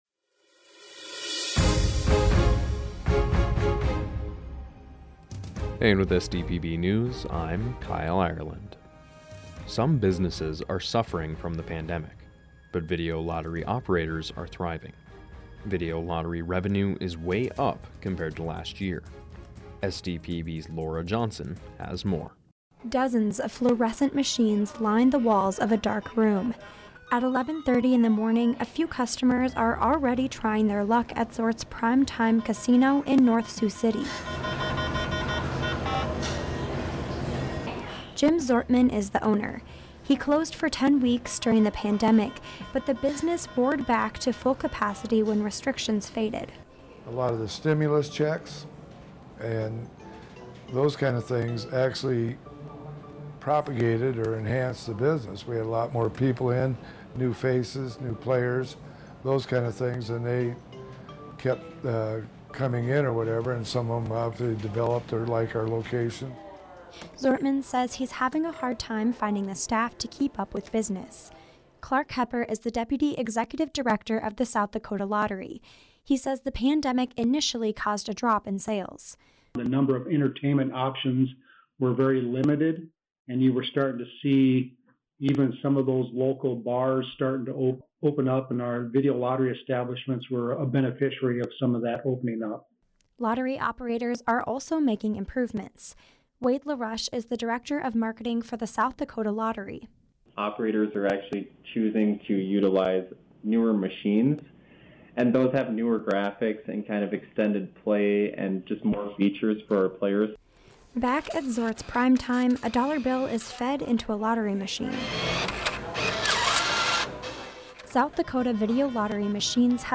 Each day, SDPB's journalism team works to bring you pertinent news coverage. We then compile those stories into one neatly formatted daily podcast so that you can stay informed.